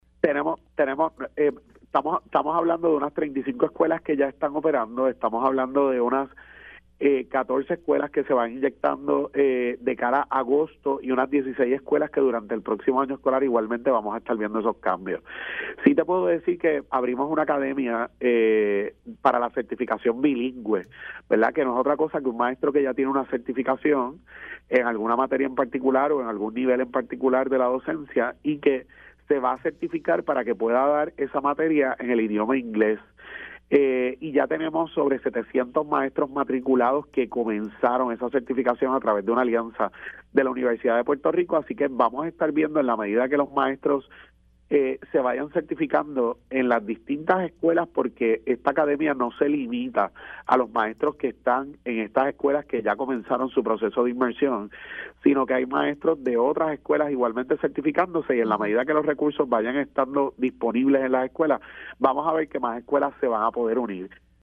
El secretario de Educación, Eliezer Ramos confirmó en Pega’os en la Mañana que ya se debe ver reflejado el aumento a los asistentes T-1 del Programa de Educación Especial en sus nóminas.